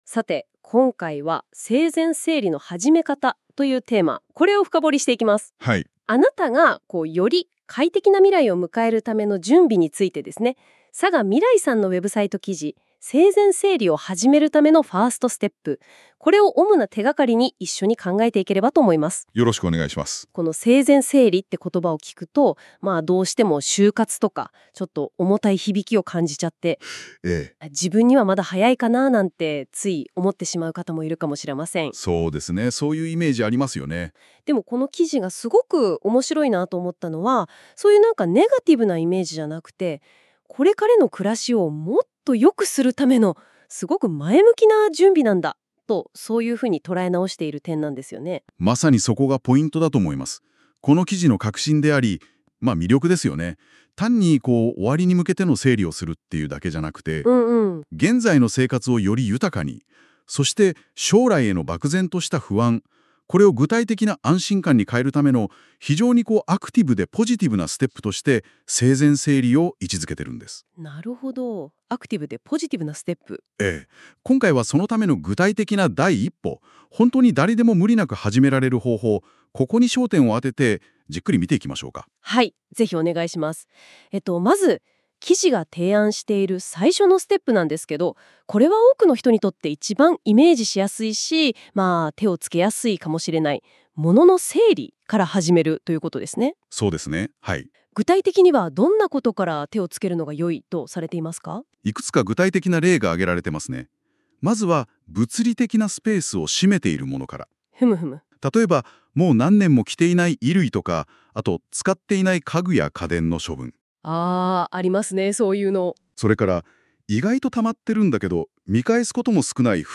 このページの内容をAI要約音声で聞きたい場合は、下の再生ボタンをクリック